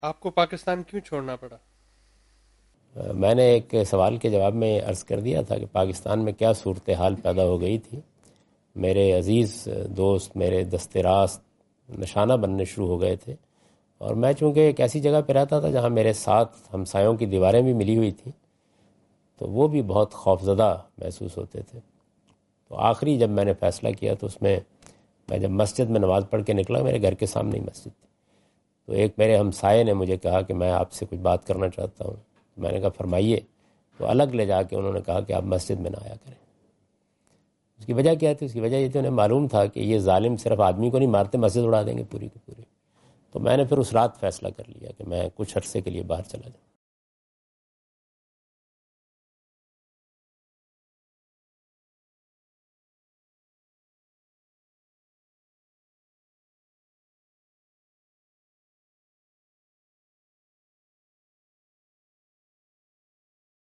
Javed Ahmad Ghamidi answer the question about "Why did Ghamidi Sahib have to leave the country?" during his Australia visit on 11th October 2015.
جاوید احمد غامدی اپنے دورہ آسٹریلیا کے دوران ایڈیلیڈ میں "غامدی صاحب کو ملک کیوں چھوڑنا پڑا؟ " سے متعلق ایک سوال کا جواب دے رہے ہیں۔